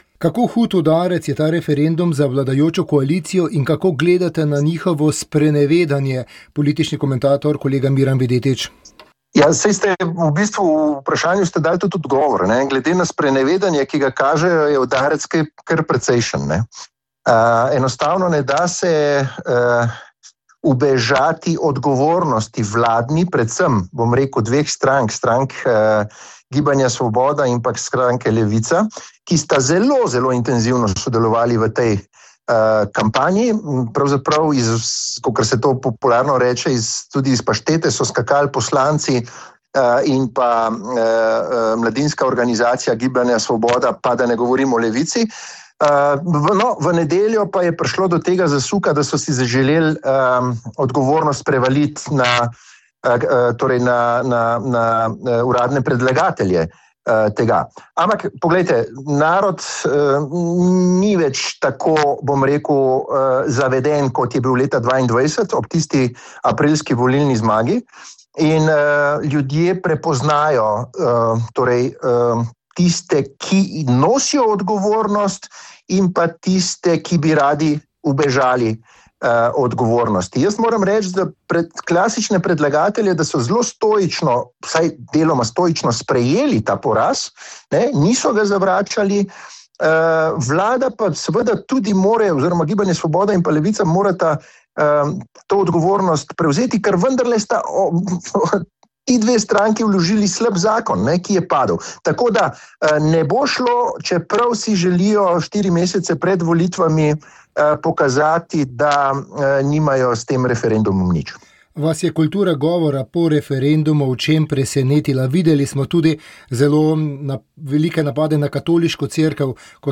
Pridiga nadškofa Antona Stresa pri spominski maši v Rovtah
V župnijski cerkvi v Rovtah je bila v nedeljo spominska maša in slovesnost za pobite domobrance ter druge žrtve prve in druge svetovne vojne ter žrtve vojne za Slovenijo. Nadškof Anton Stres je v pridigi spomnil na nedavni evropski dan spomina na žrtve treh totalitarizmov, ki je po njegovem posvečen resnici o teh režimih in spoštljivemu spominu njihovih žrtev.